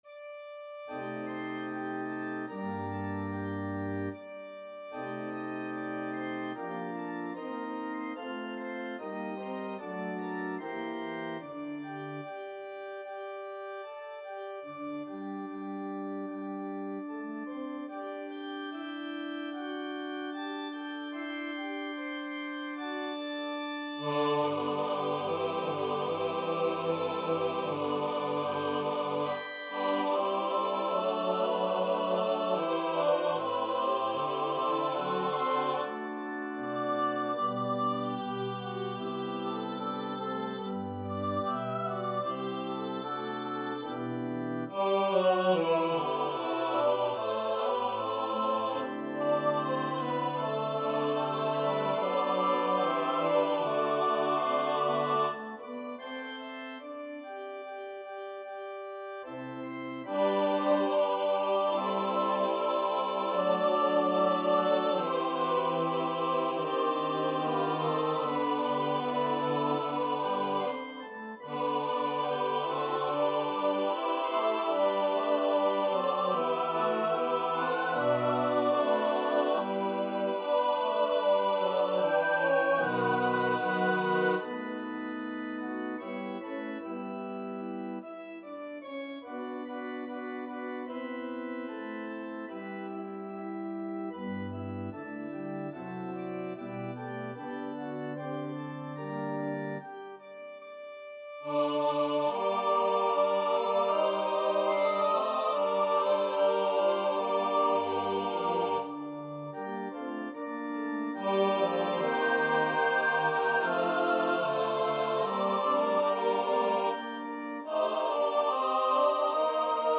• Music Type: Choral
• Voicing: SATB
• Accompaniment: Organ
graceful music concluding in the major mode